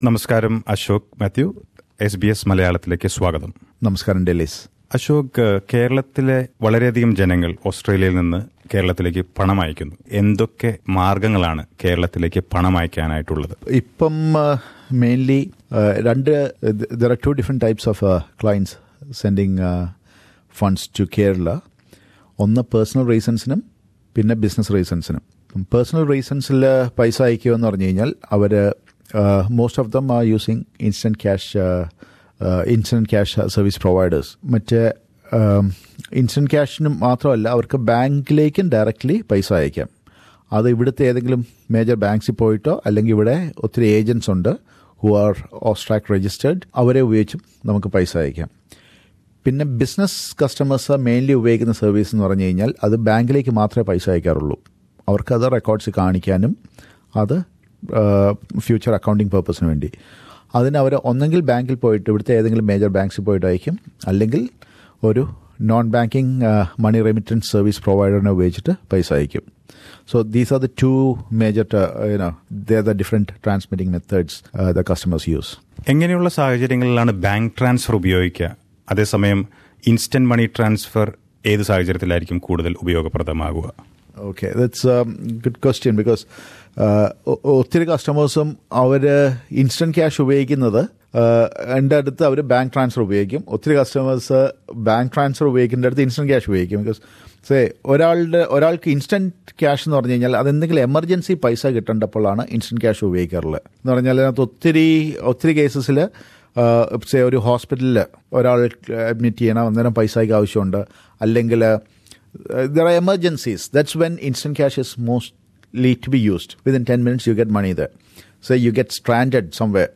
Malayalees in Australia send money to Kerala for various reasons including family maintenance and business. Let us listen to an interview regarding the important things to remember while sending money overseas.